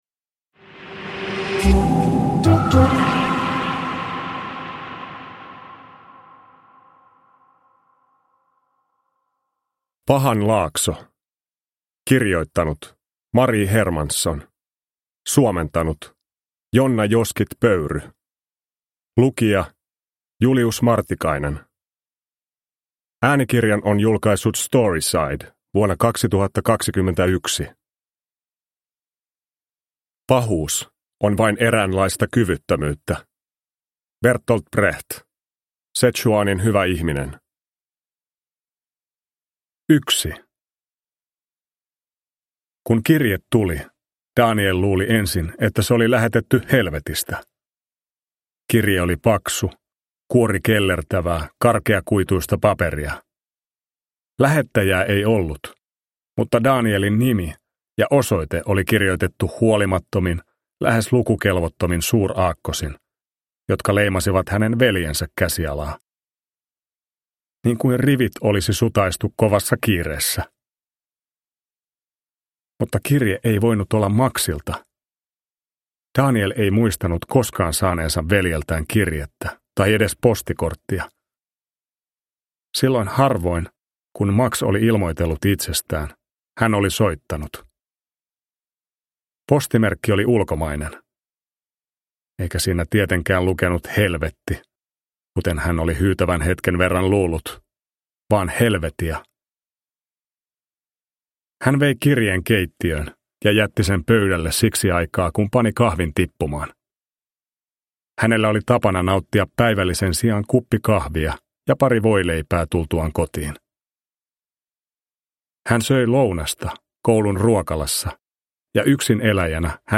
Pahan laakso – Ljudbok – Laddas ner